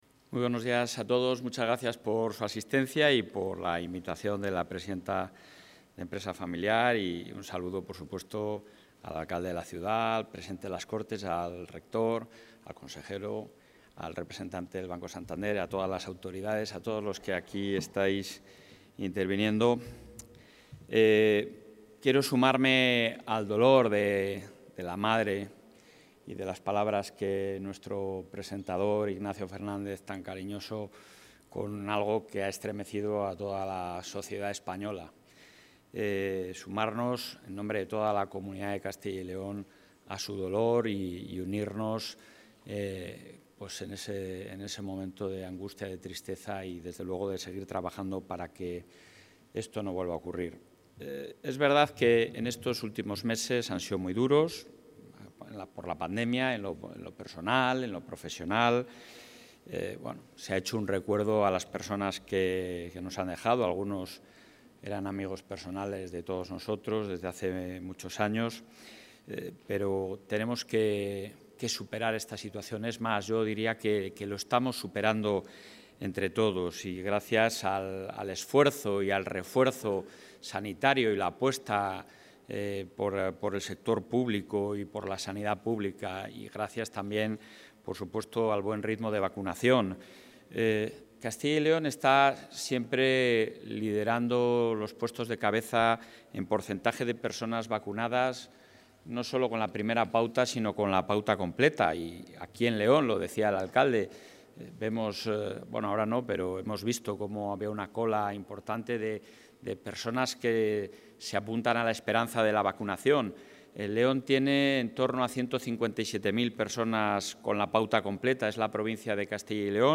Intervención presidente.